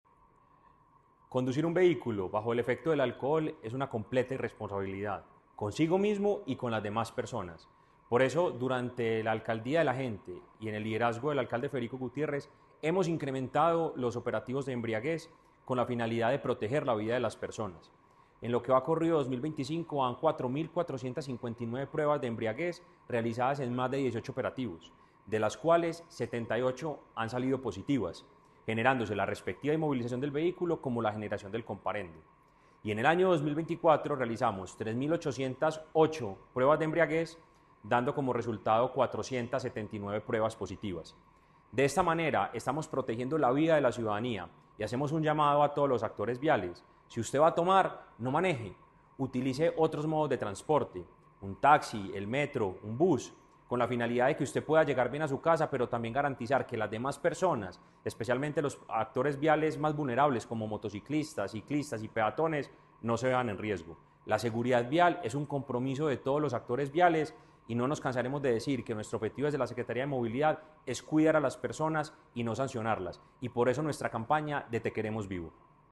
Declaraciones_ecretario-de-Movilidad-Mateo-Gonzalez-Benitez-mp3.mp3